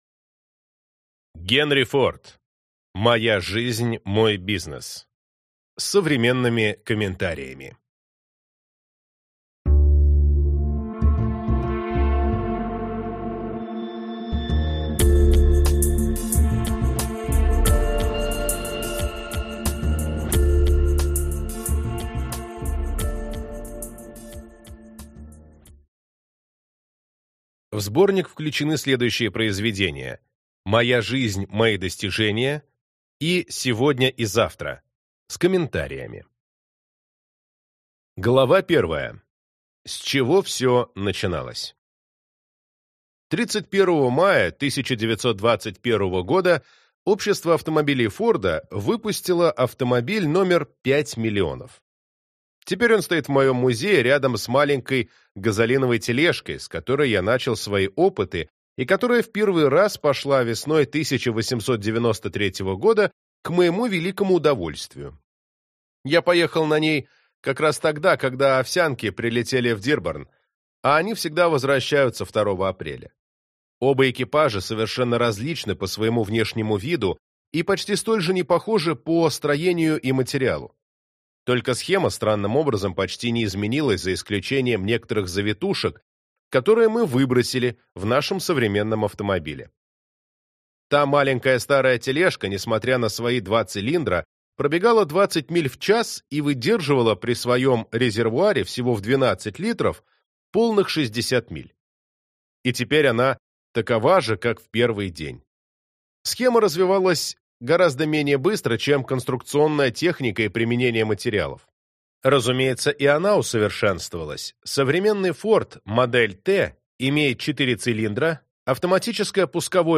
Аудиокнига Моя жизнь, мой бизнес. С современными комментариями | Библиотека аудиокниг